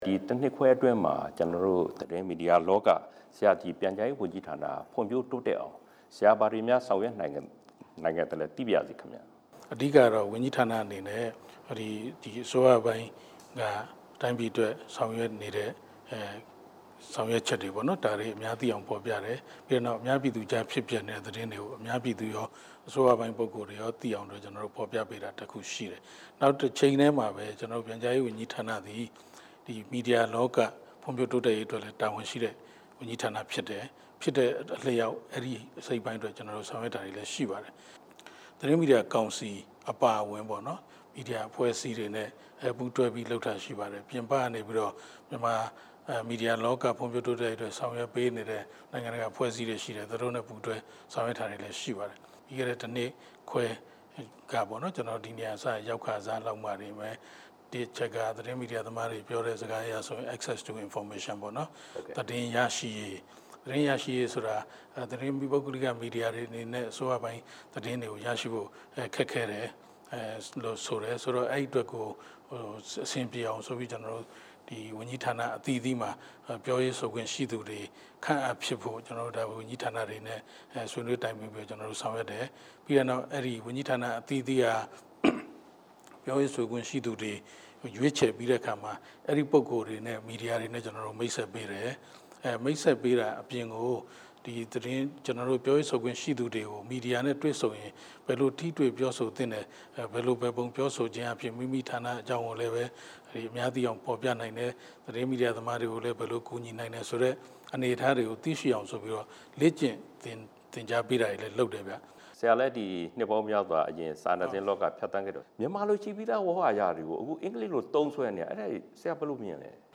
သတင်းမီဒီယာ အခြေအနေ ပြန်ကြားရေးဝန်ကြီး နဲ့ မေးမြန်းချက်
နေပြည်တော်ရှိ ပြန်ကြားရေးဝန်ကြီးဌာန ရုံးခန်းမှာ စက်တင်ဘာလ ၂၆ နေ့က အခုလို တွေ့ဆုံမေးမြန်းခဲ့ပါတယ်။